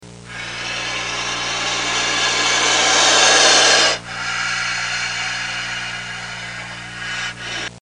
Cobra Hiss